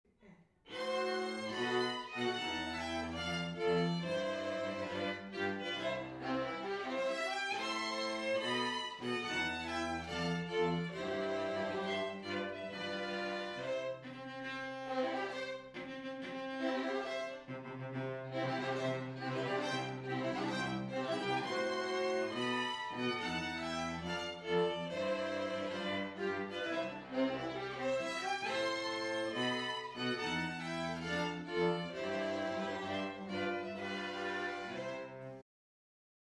We have made a home recording of some snippets of the most popular Processionals and Recessionals as an audio aid in making your selections.